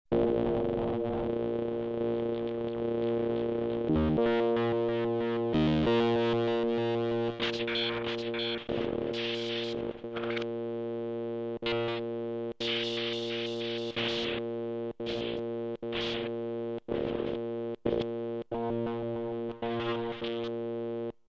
16 Bit Digital Synthesizer
demo Ring modulation